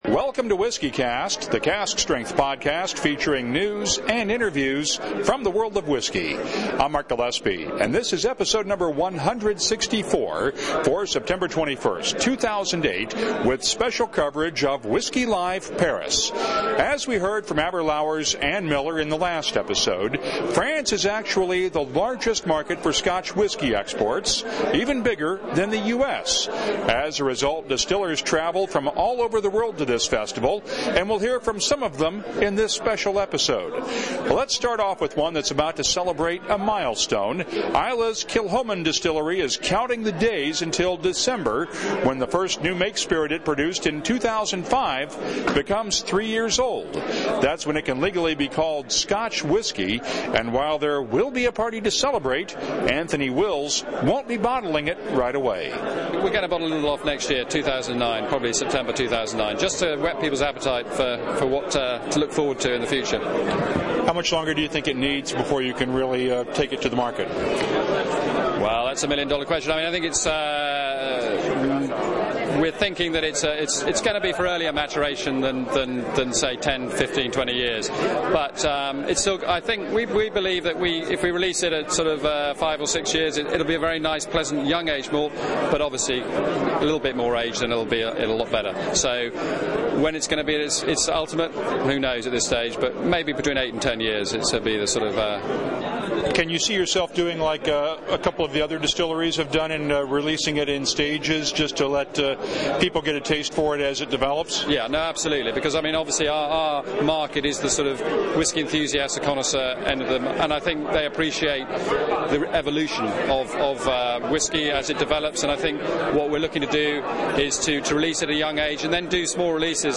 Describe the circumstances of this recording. Home - WhiskyCast Episodes - Episode 164: September 21, 2008 Episode 164: September 21, 2008 Time now for part two of WhiskyCast’s special coverage of Whisky Live Paris!